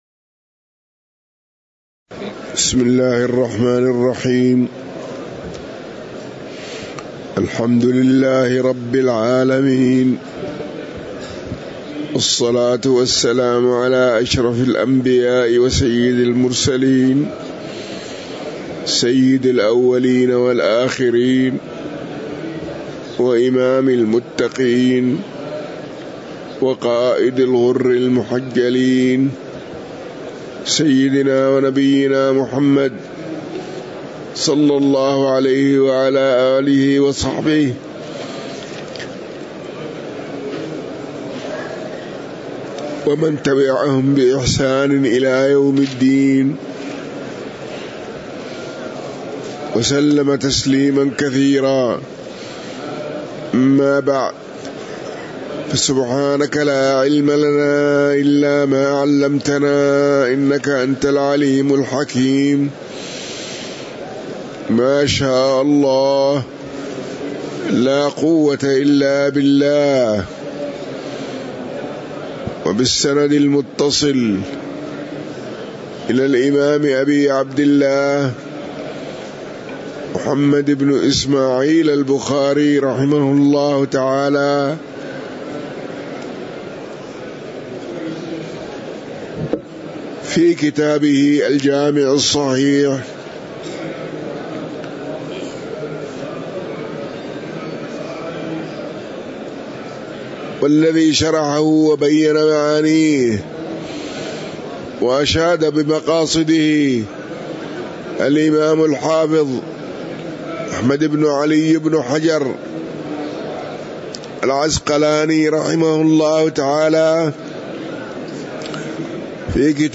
تاريخ النشر ١٢ رمضان ١٤٤٤ هـ المكان: المسجد النبوي الشيخ